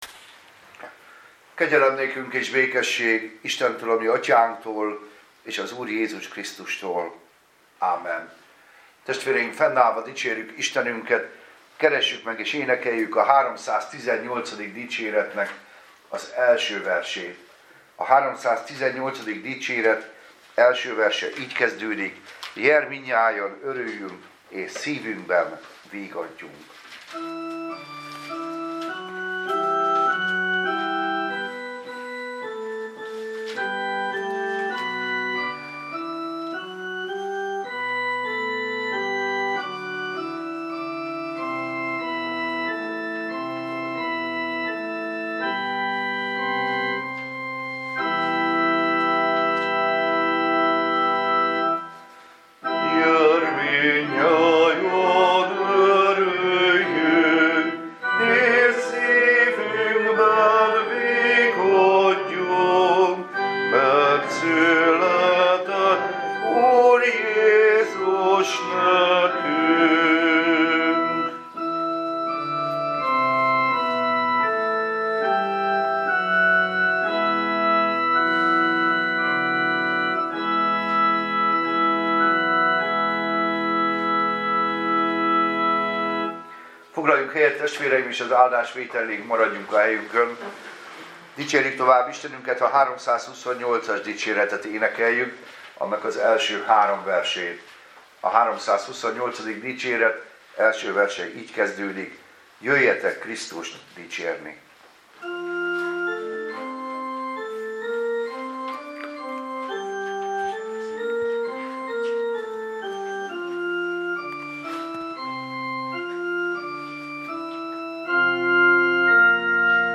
Karácsony másnapi istentisztelet
Igehirdetés